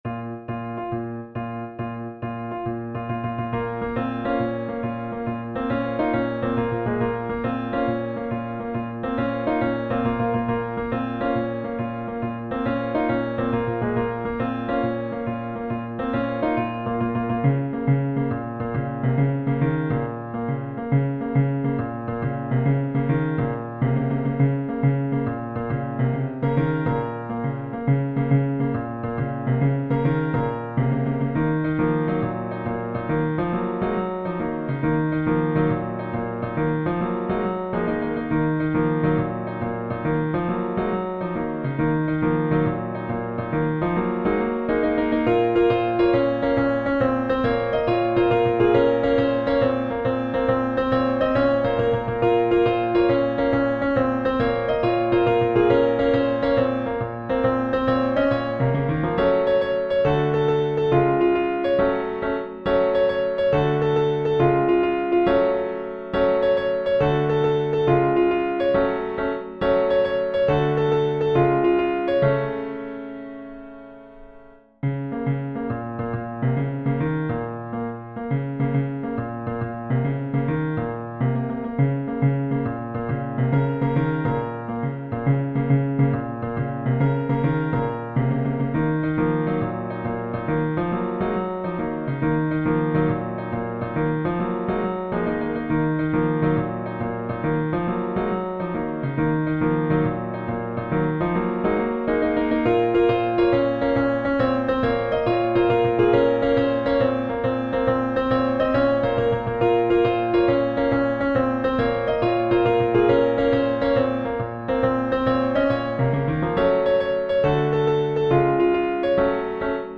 SATB choir